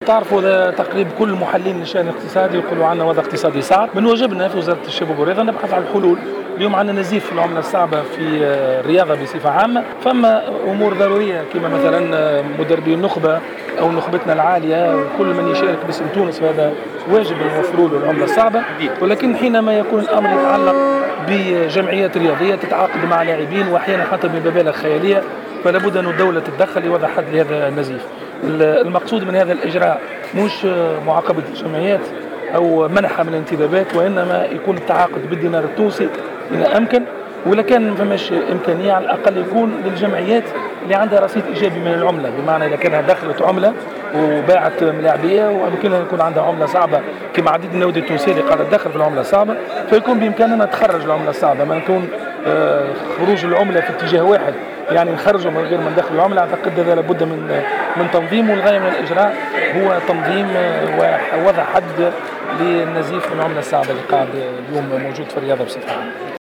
اكد وزير الشباب و الرياضة ماهر بن ضياء في تصريح لجوهرة اف ام ان الوضع الاقتصادي لبلادنا في الفترة الحالية يتطلب بعض الاجراءات التقشفية المتعلقة خاصة بالعملة الصعبة .